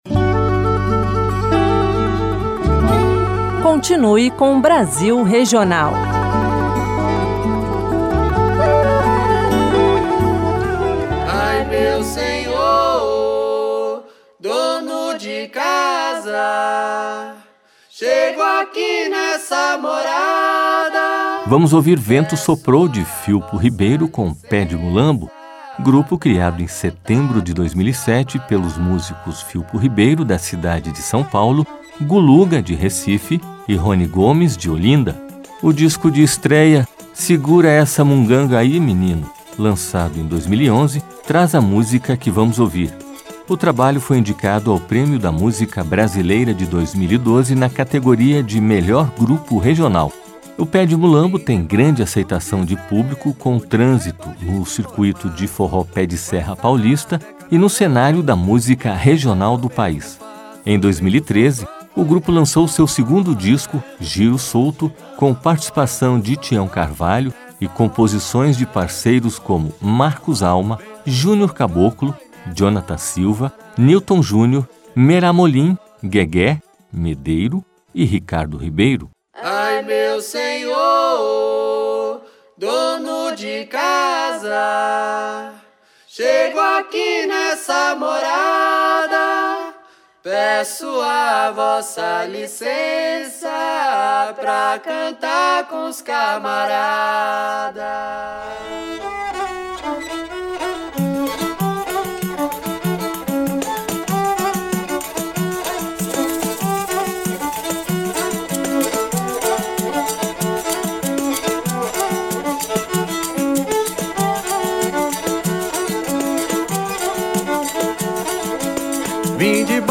Programa semanal que leva o ouvinte a descobrir os sons do Brasil